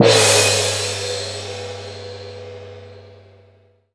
GONG 1.wav